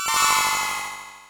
snd_saber3.wav